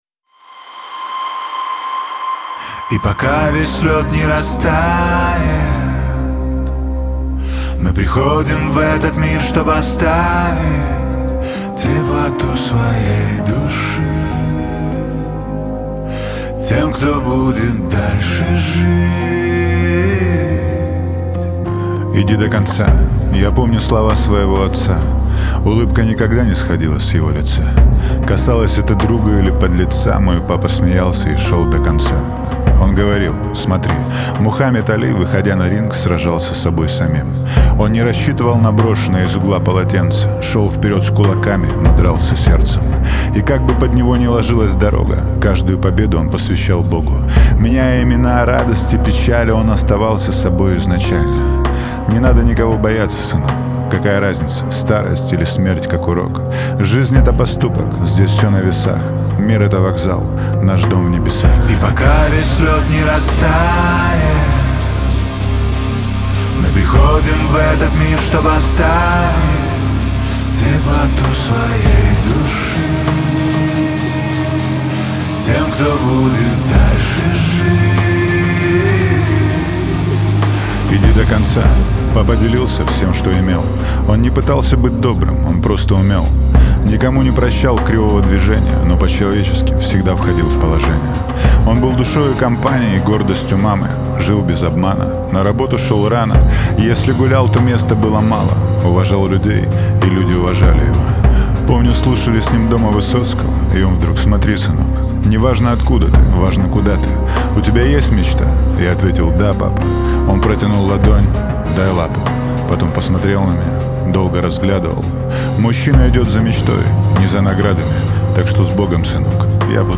выполненная в жанре рок с элементами хардкор.